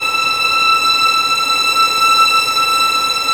Index of /90_sSampleCDs/Roland L-CD702/VOL-1/STR_Vlns Bow FX/STR_Vls Sul Pont